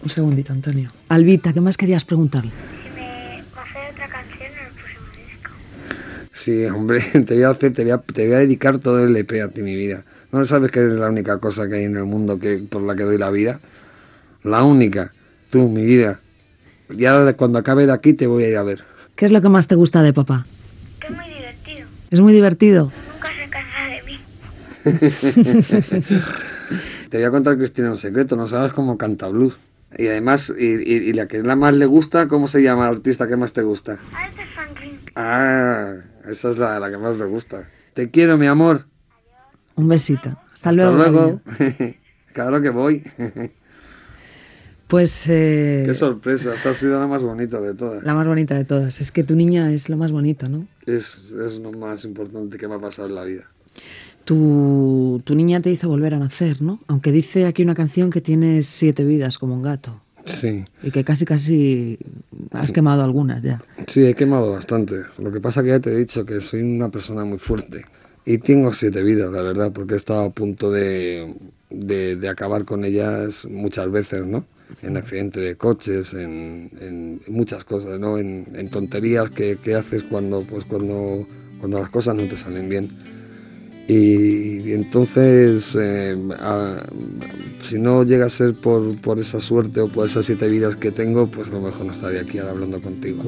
Fragment d'una entrevista al cantautor Antonio Flores amb la intervenció telefònica de la seva filla Alba Flores.
Entreteniment